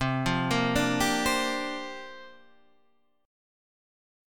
C 9th Suspended 4th